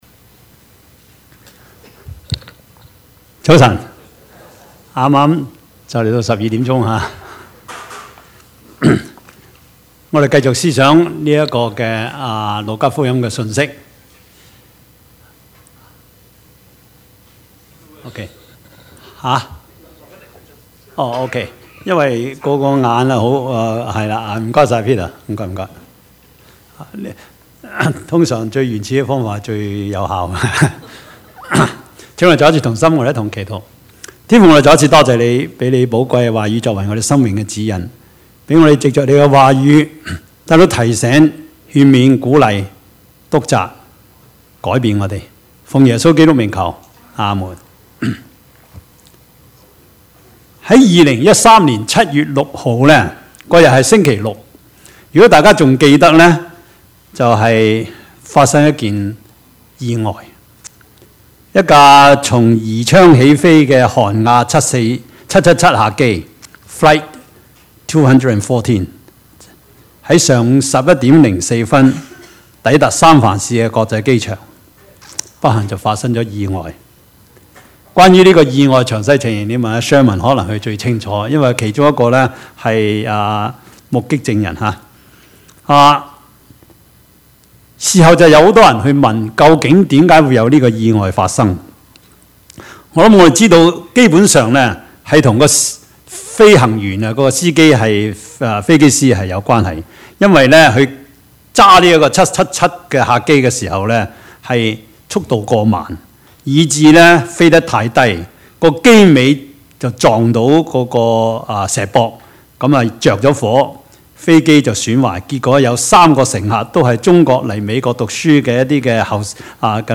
Service Type: 主日崇拜
Topics: 主日證道